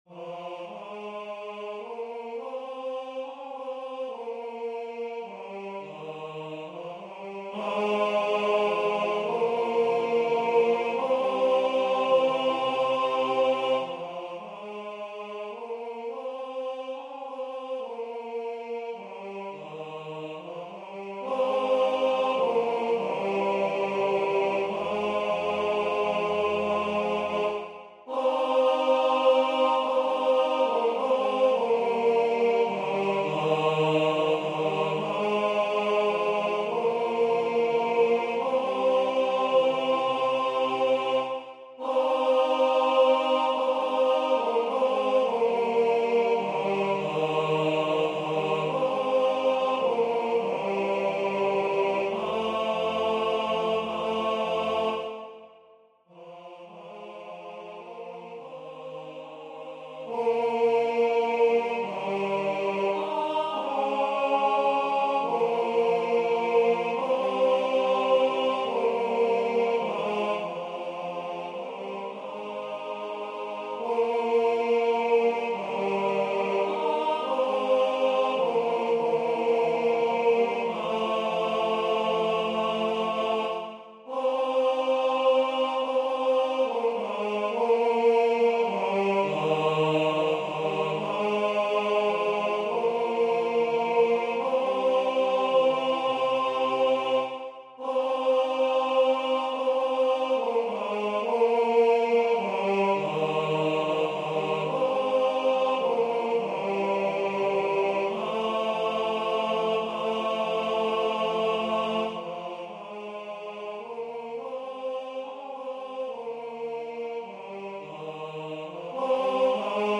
Tenor 2 Baritone Bass